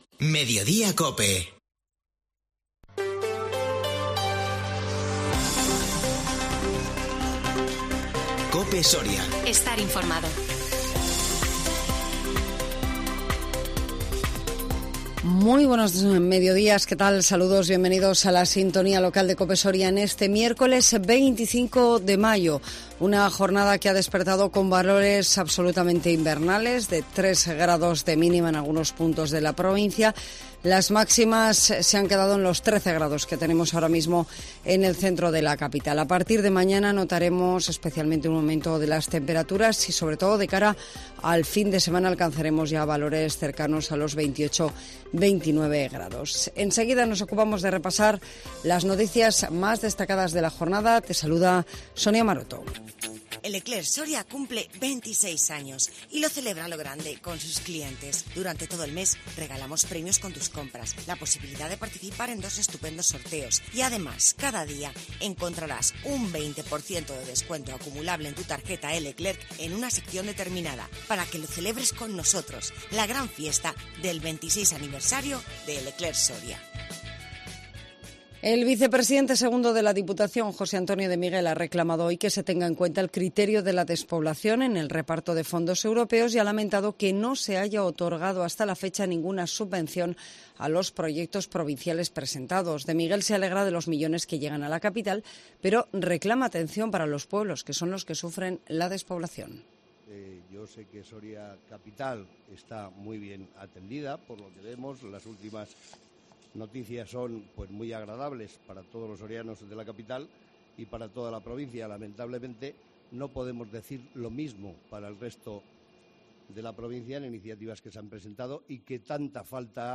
INFORMATIVO MEDIODÍA COPE SORIA 25 MAYO 2022